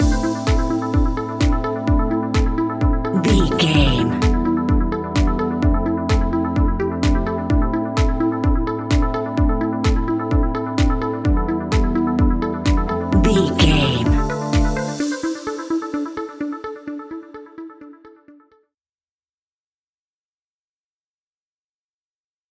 Aeolian/Minor
dark
futuristic
groovy
synthesiser
drum machine
house
electro house
synth leads
synth bass